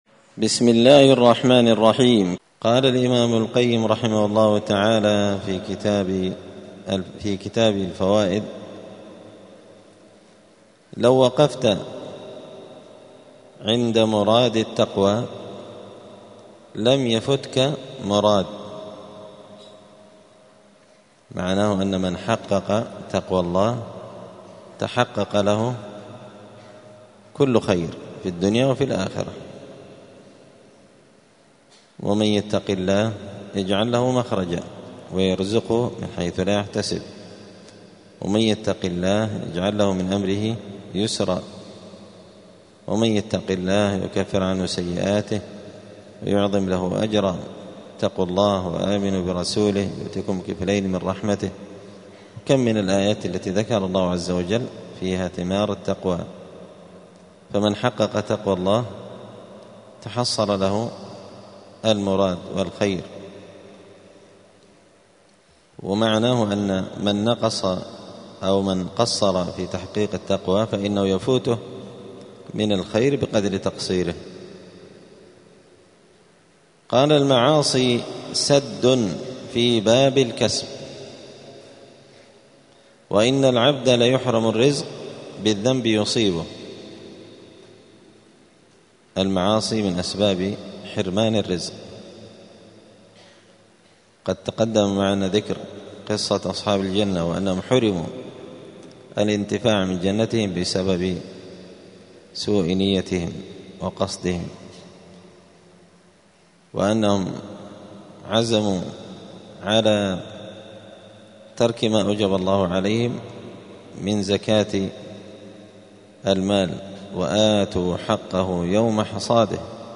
*الدرس السادس والعشرون (26) {فصل: المعاصي سد في باب الكسب}*
دار الحديث السلفية بمسجد الفرقان قشن المهرة اليمن 📌الدروس الأسبوعية